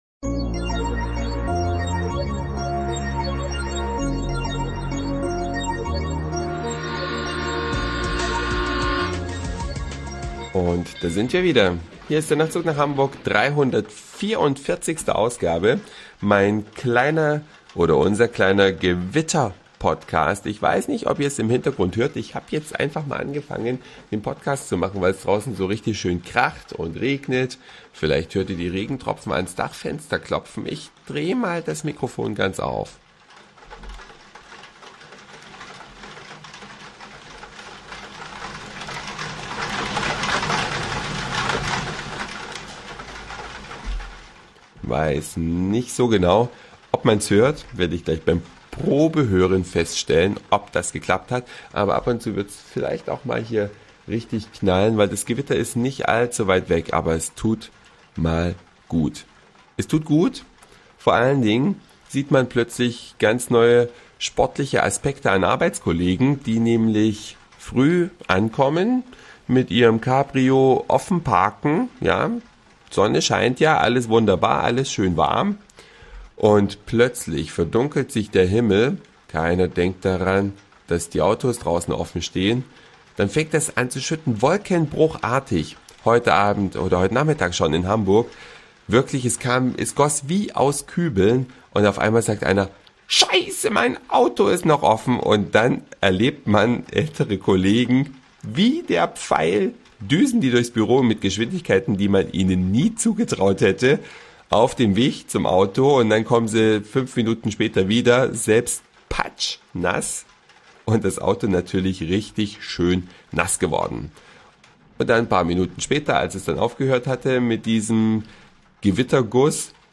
Eine Reise durch die Vielfalt aus Satire, Informationen, Soundseeing und Audioblog.
Aufzeichnung war es relativ ruhig.